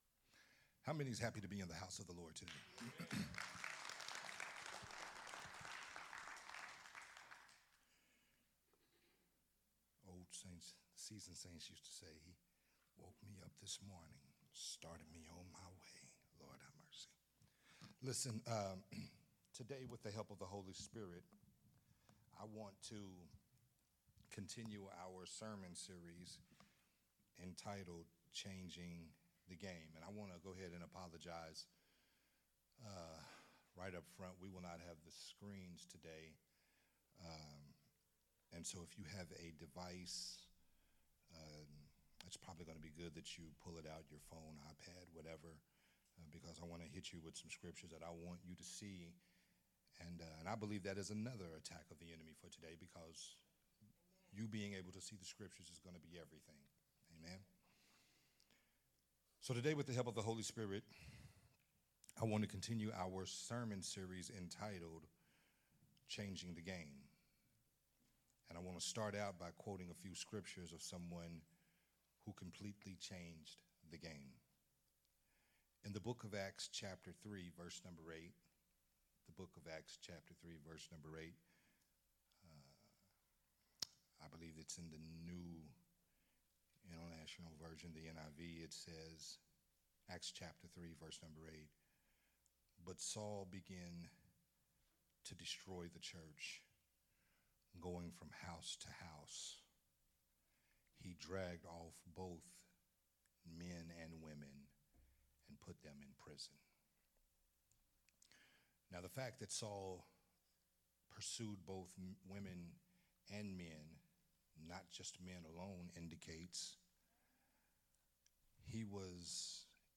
Part 3 of the sermon series, “Changing the Game”